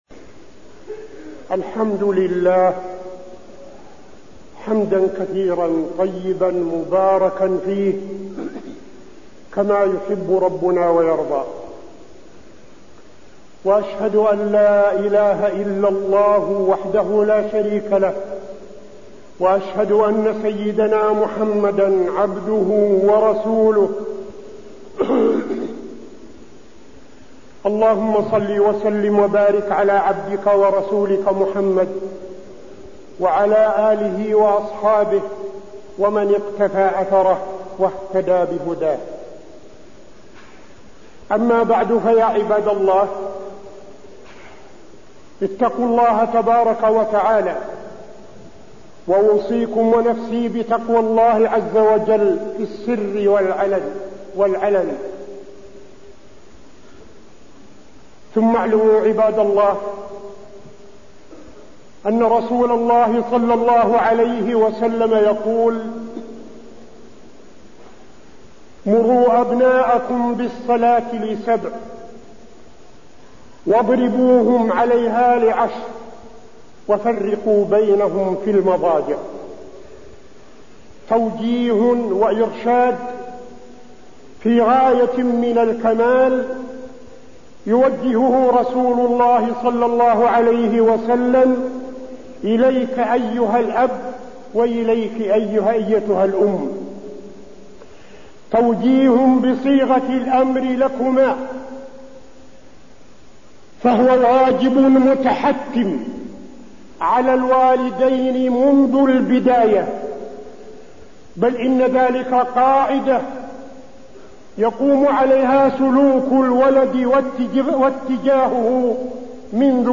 تاريخ النشر ٨ رجب ١٤٠٥ هـ المكان: المسجد النبوي الشيخ: فضيلة الشيخ عبدالعزيز بن صالح فضيلة الشيخ عبدالعزيز بن صالح تربية الأولاد